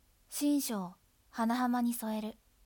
タイトルコール